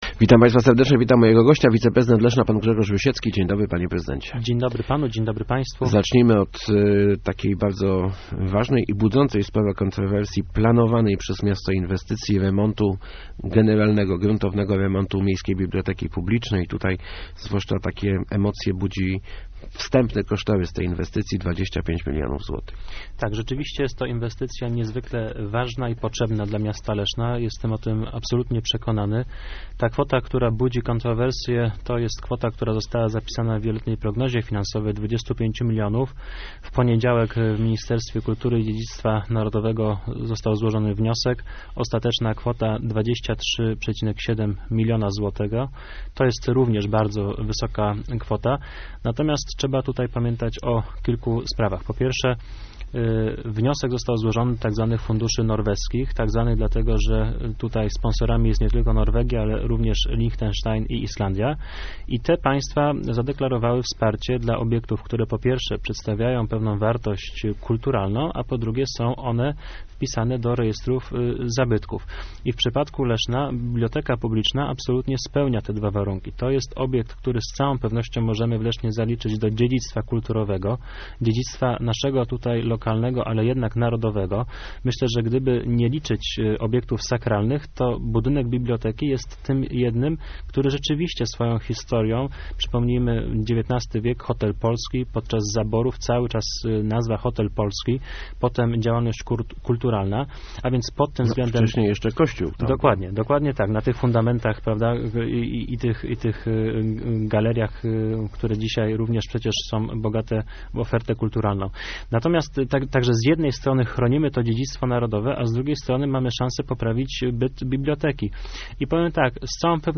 Gościem Kwadransa był wiceprezydent Grzegorz Rusiecki ...